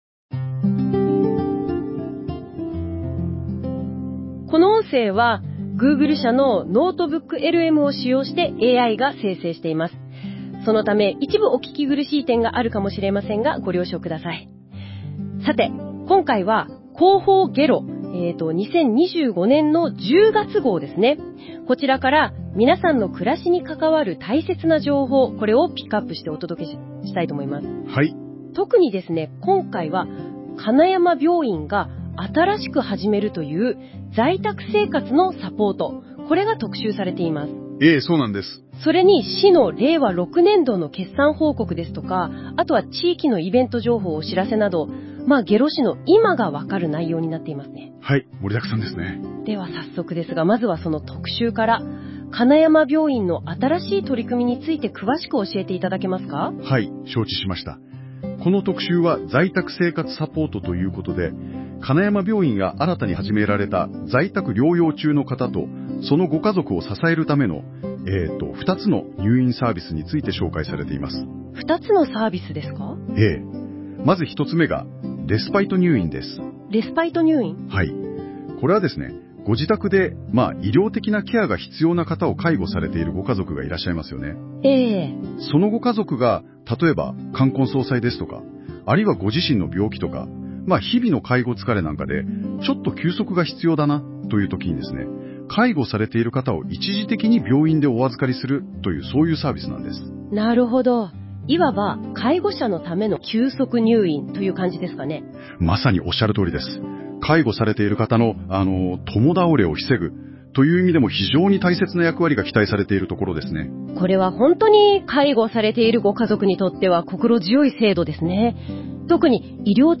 広報げろを解説した音声データを公開しています！
広報げろ10月号（音声データ） [その他のファイル／2.44MB] ～行政情報の音声提供サービスについて～ 下呂市では、令和7年5月から市民の皆さんの利便性向上のため、生成AIを活用した行政情報の音声提供サービスを試験的に開始します。